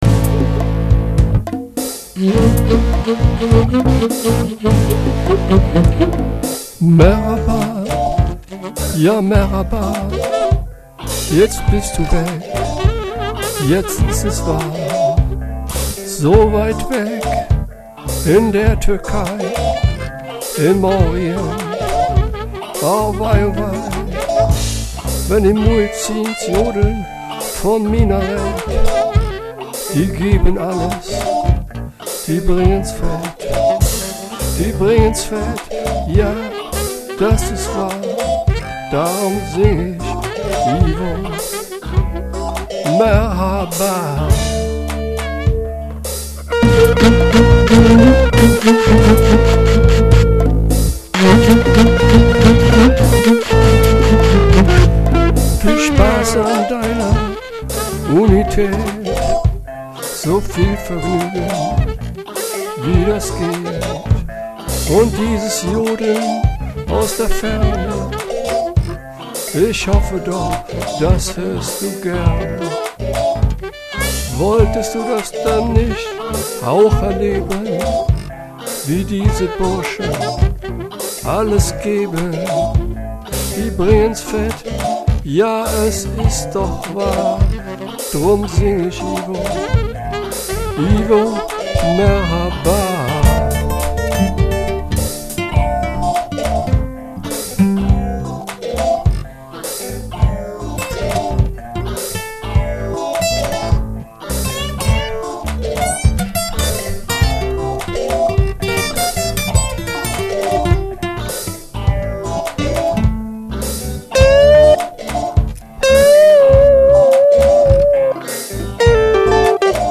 Ähnliches bekomme ich als Sänger, Gitarrist, Altsaxoponist  und hilfsweiser Bassgitarrist spätestens 2013 trotz Verletzungsfolgen wieder hin. Mit Ausnahme weiblicher Stimmen und teilweise nur bearbeiteten Rhythmen aus dem Internet ist jeder Ton hier von mir; z.T. mit den virtuellen Möglichkeiten von GarageBand ein gegeben.